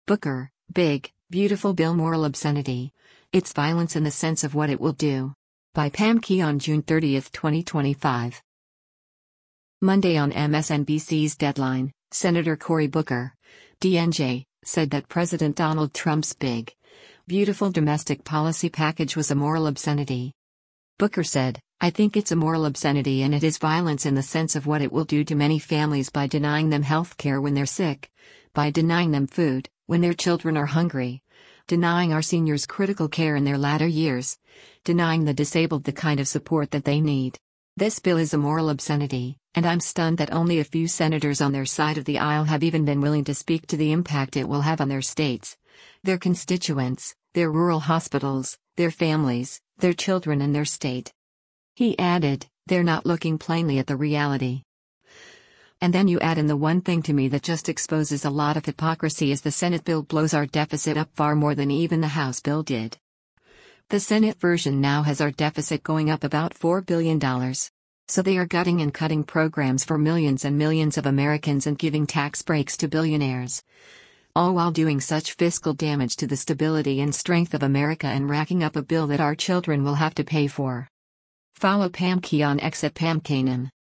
Monday on MSNBC’s “Deadline,” Sen. Cory Booker (D-NJ) said that President Donald Trump’s “big, beautiful” domestic policy package was a “moral obscenity.”